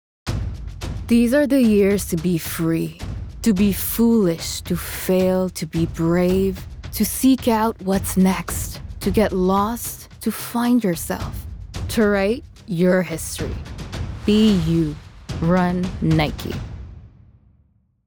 Publicité (Nike) - ANG